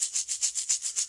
发现 " 摇蛋器07
描述：Shaker打击乐器自制
Tag: 振动筛 打击乐 国产